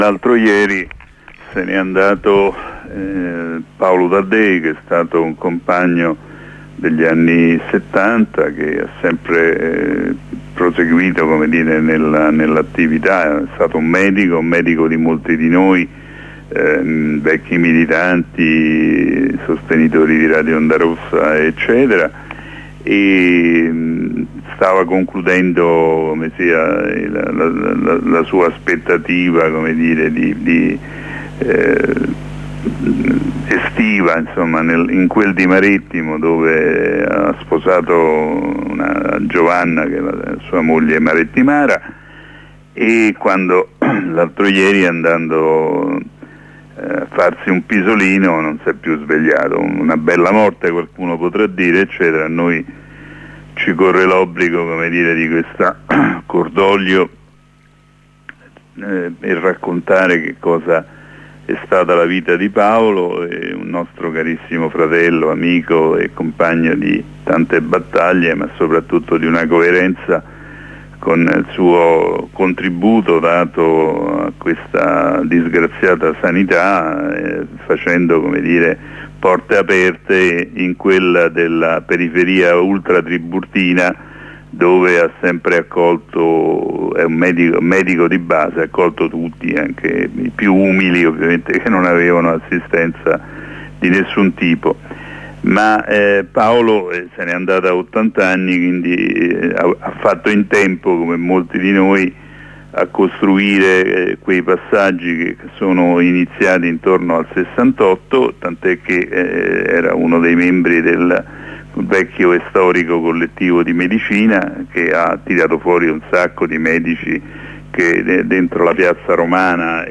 Corrispondenza con una compagna di Milano dal corteo